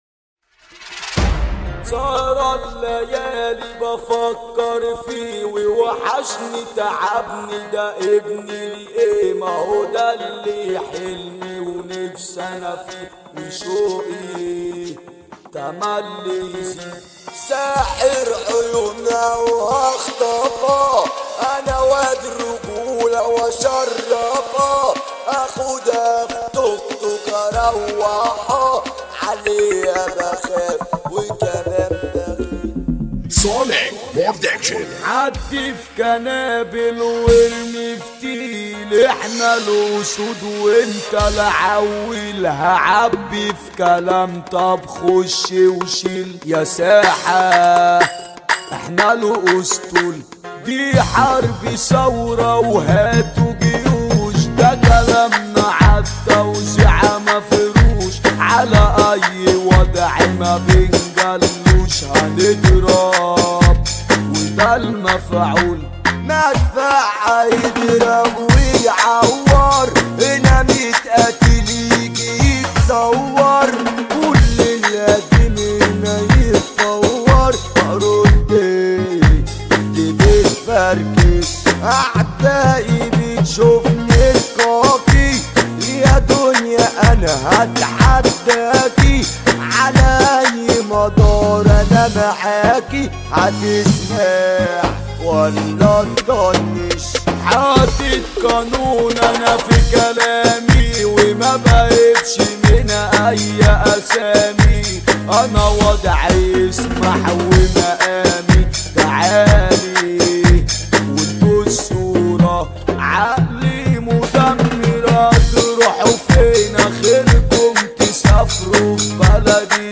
اغانى مهرجانات
النوع: [شعبي] مدة الأغنية: [3:45]